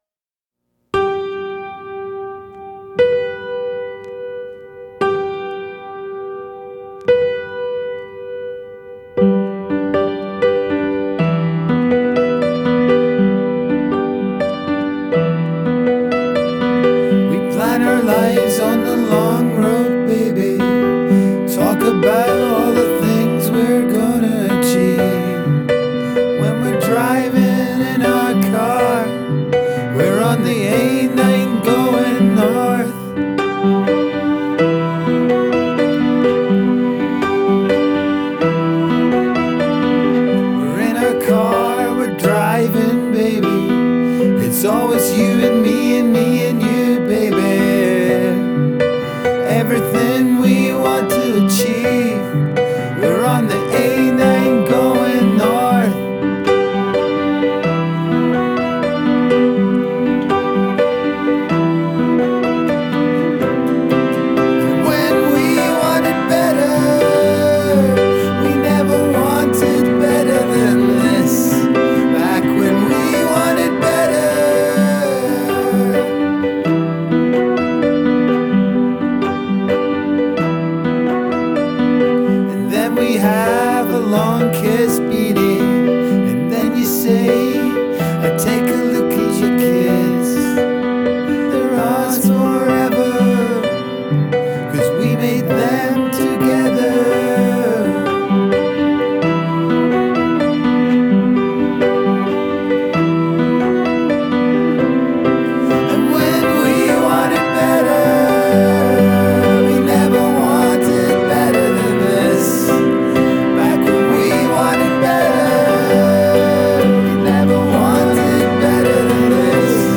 Genre: acoustic, experimental, folk rock